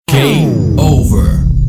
diesound.ogg